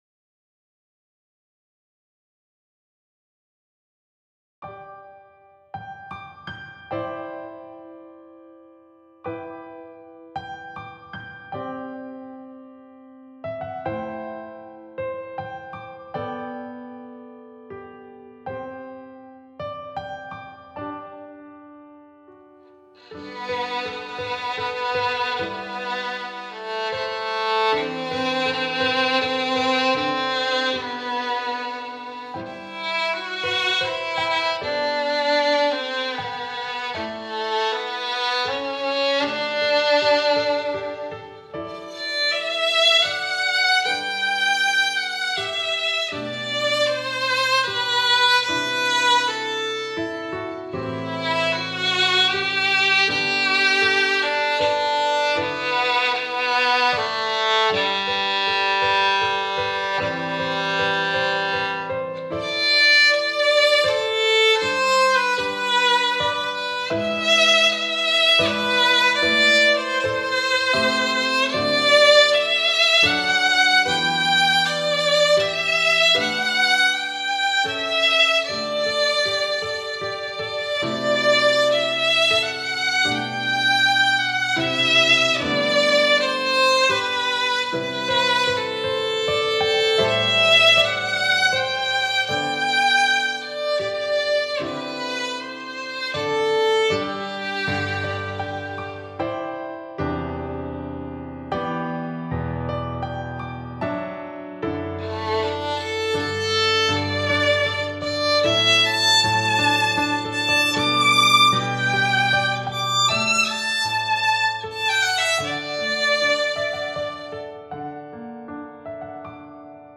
Instrumentation: 2 Violins, Piano
This arrangement is for 2 violins with piano accompaniment.
Download free recording of piano accompaniment and violin 1